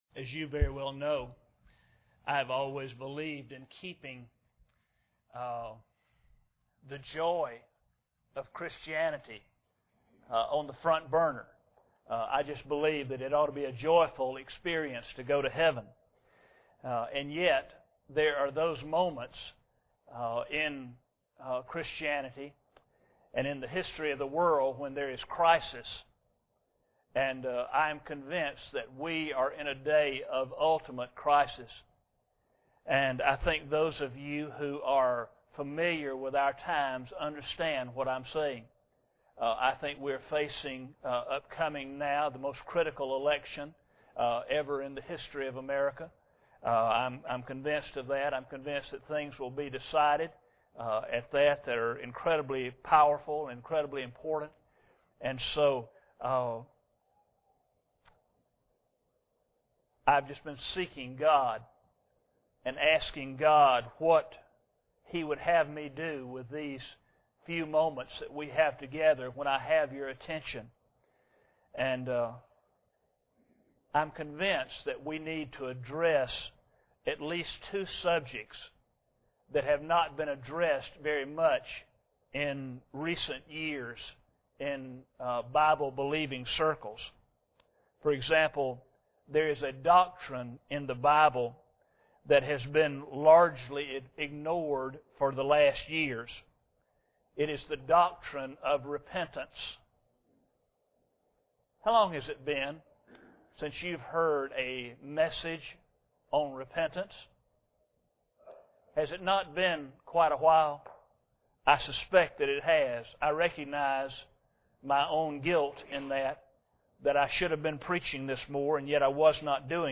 Luke 24:46-48 Service Type: Sunday Morning Bible Text